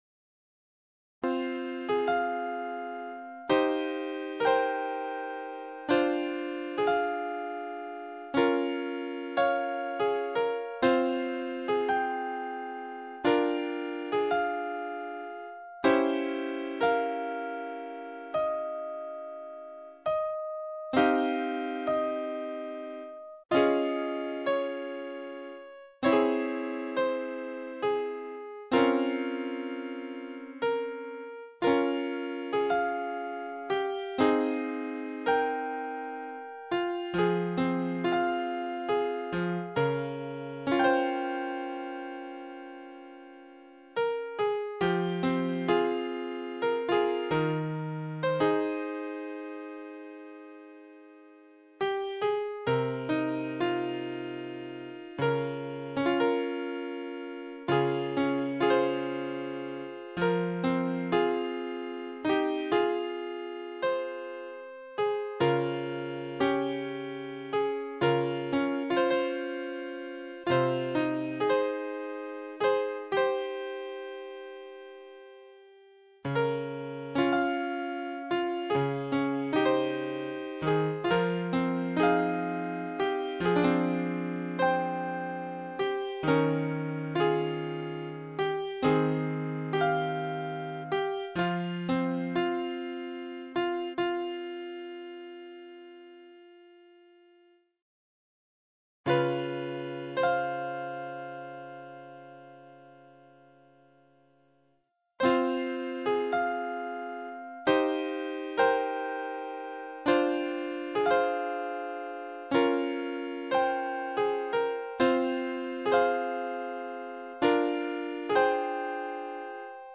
Sad sounding Solo Piano Piece Style